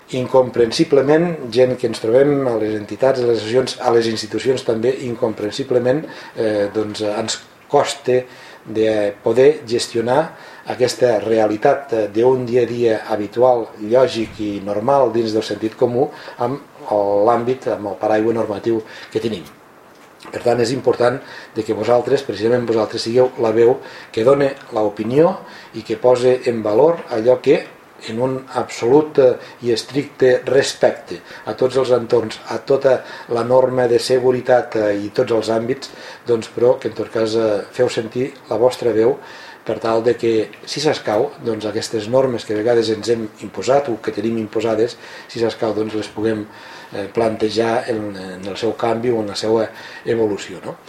El president de la Diputació de Lleida, Joan Reñé, explica que fòrums com aquest són els més adequats per fer sentir la veu i si s'escau debatre un canvi o evolució de les normes en favor del desenvolupament econòmic respectant l’entorn ambiental